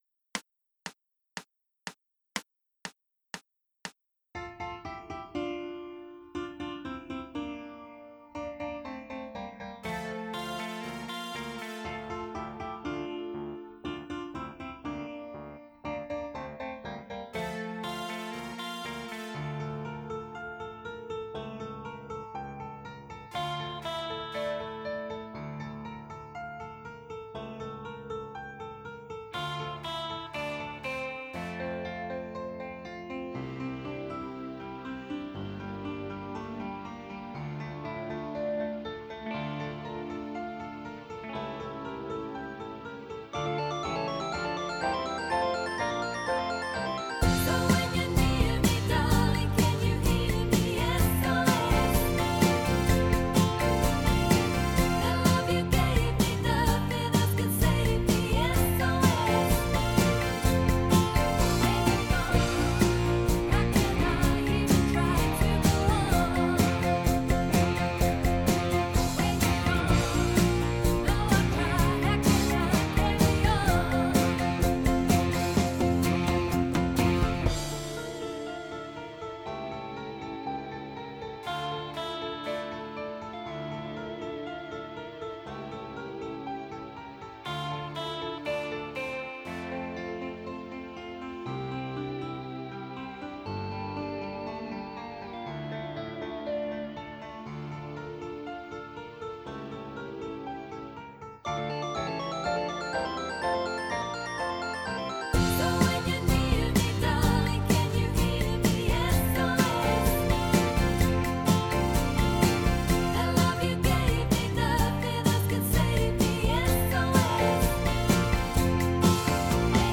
4 VOCALS